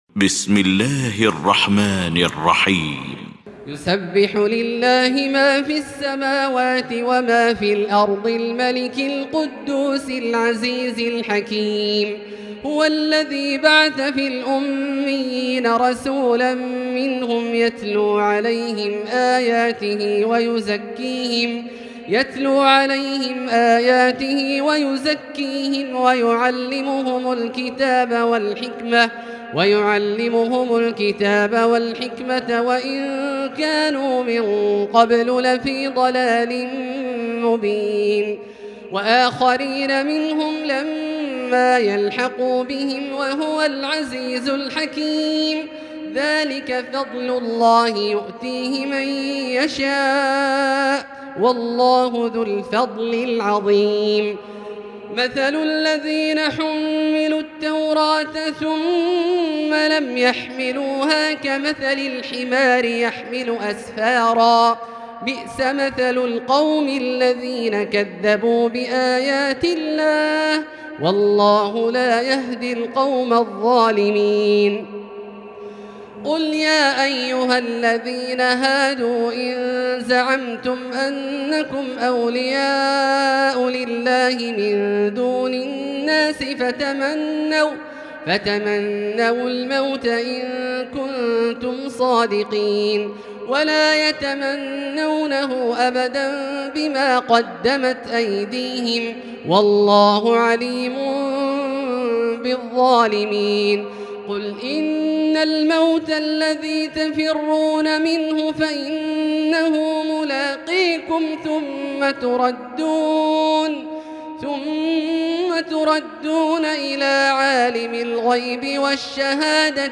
المكان: المسجد الحرام الشيخ: فضيلة الشيخ عبدالله الجهني فضيلة الشيخ عبدالله الجهني الجمعة